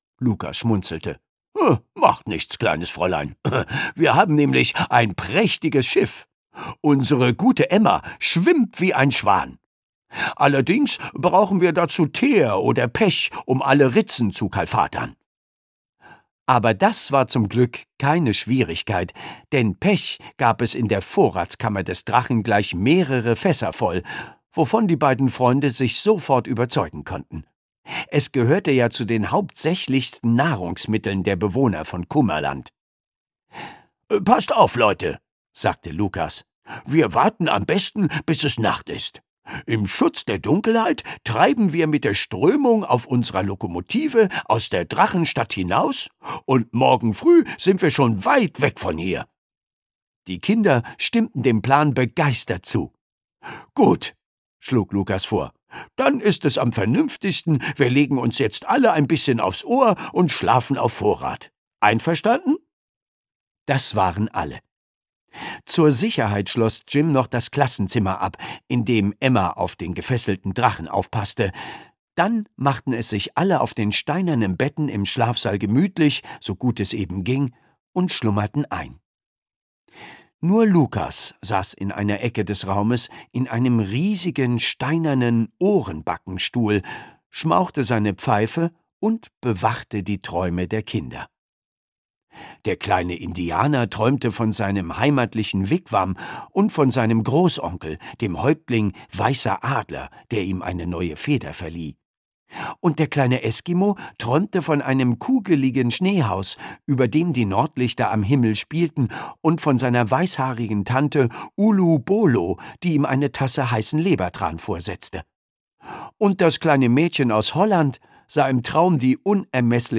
2011 | 1. Auflage, Ungekürzte Ausgabe